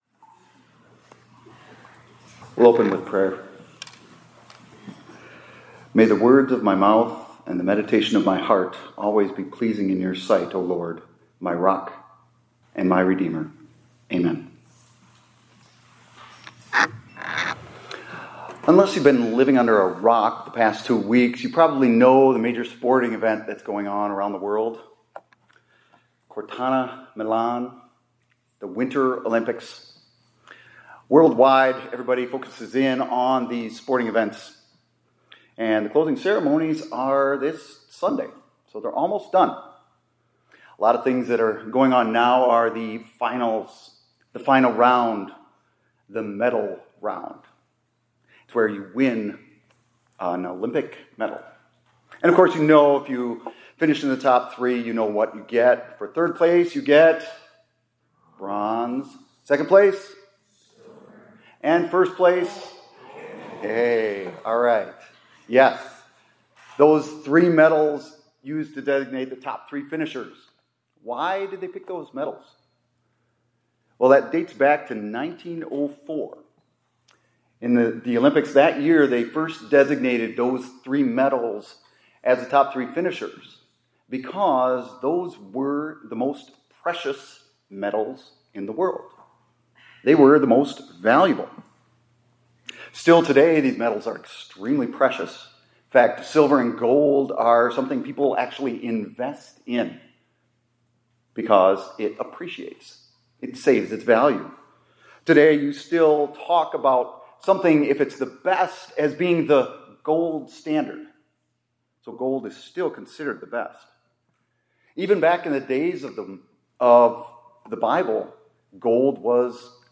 2026-02-20 ILC Chapel — Three Things are More Precious Than Gold